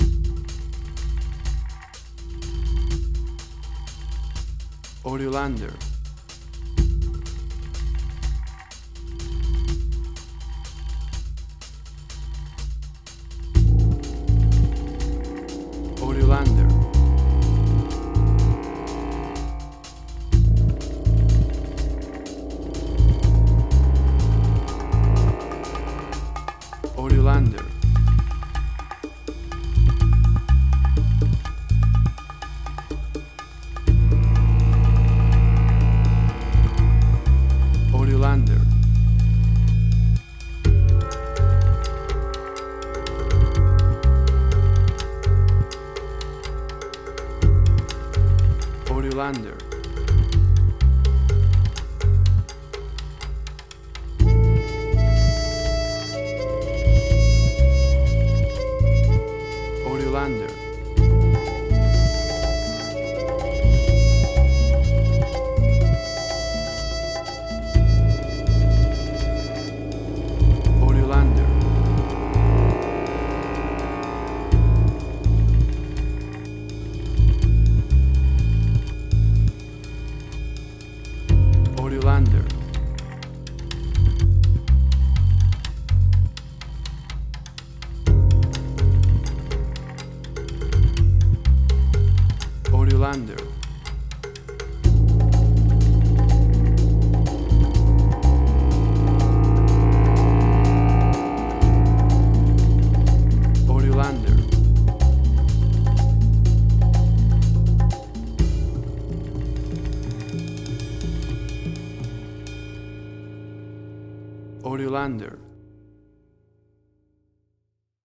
Modern Film Noir.
Tempo (BPM): 124